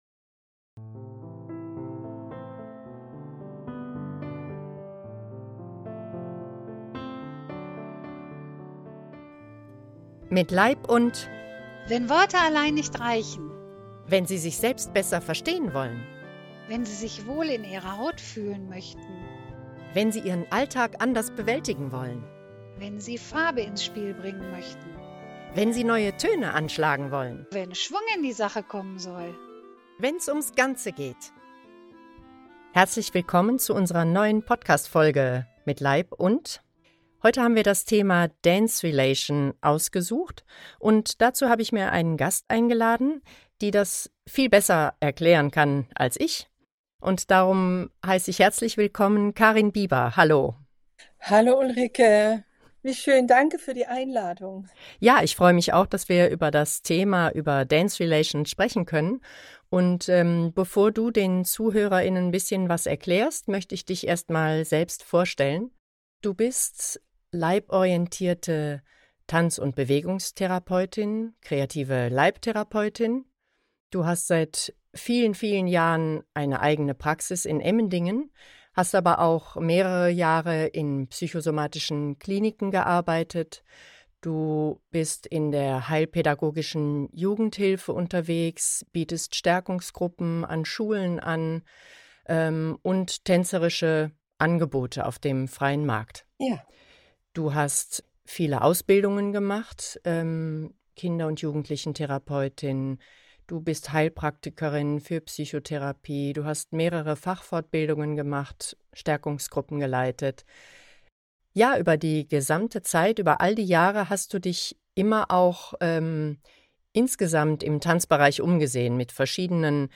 Musikbeispielen